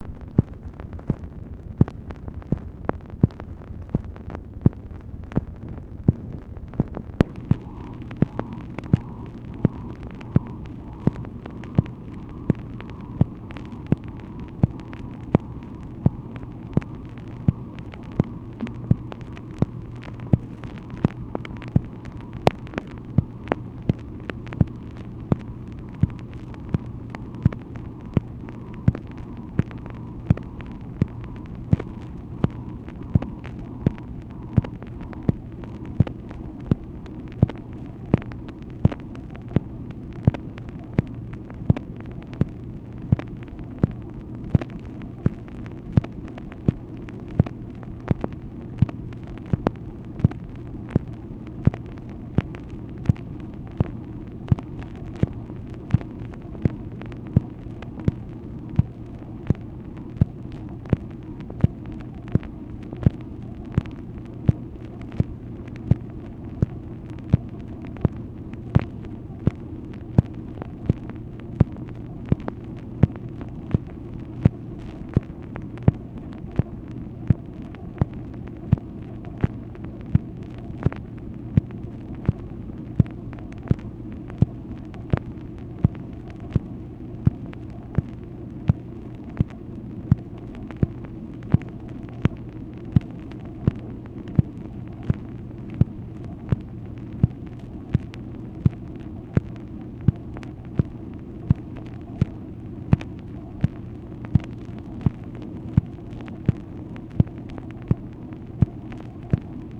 MACHINE NOISE, August 29, 1966
Secret White House Tapes | Lyndon B. Johnson Presidency